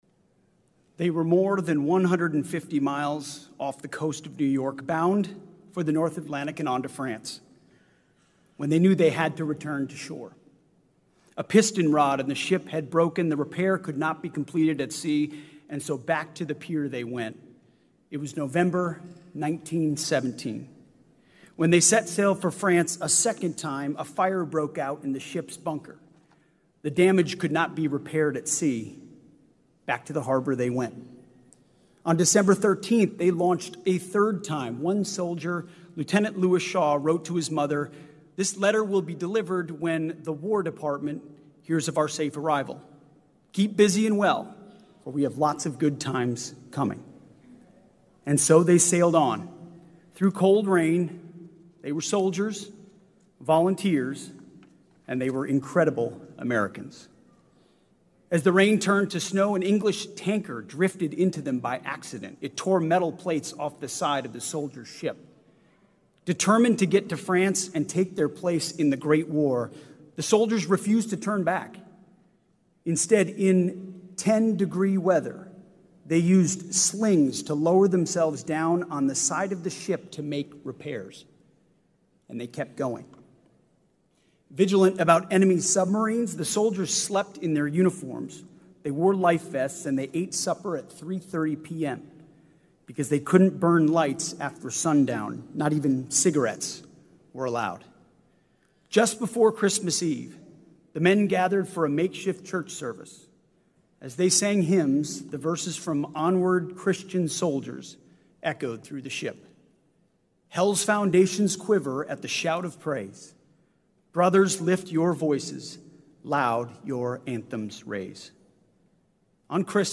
Remarks at the Congressional Gold Medal Ceremony for the Harlem Hellfighters
delivered 3 September 2025, Emancipation Hall, U.S. Capitol Visitor Center, Washington, D.C.